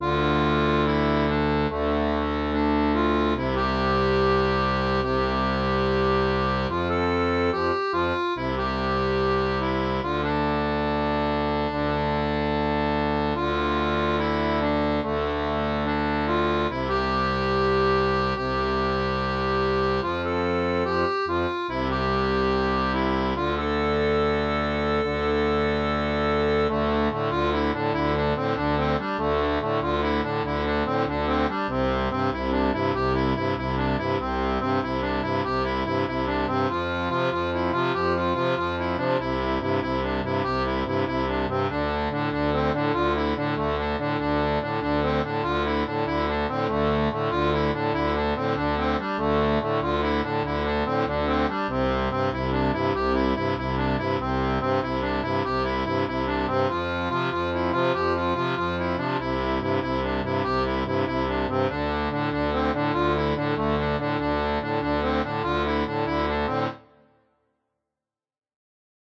Musique traditionnelle